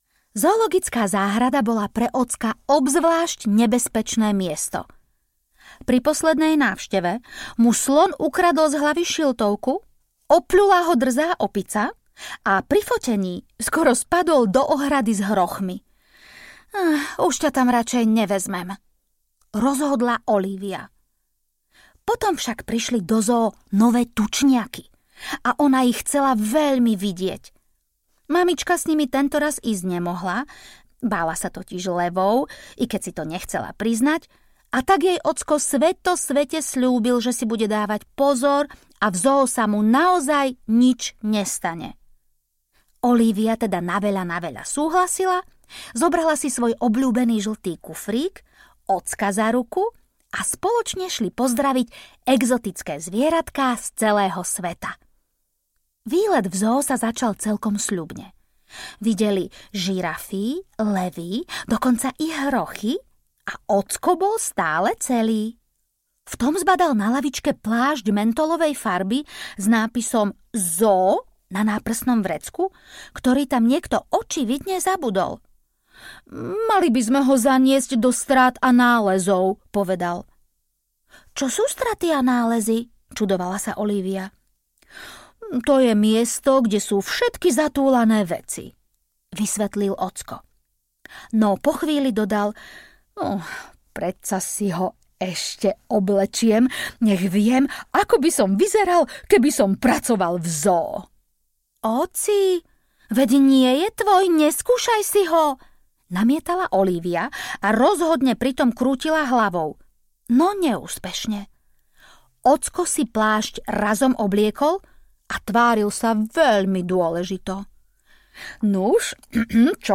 Olívia, dávaj pozor na ocka audiokniha
Ukázka z knihy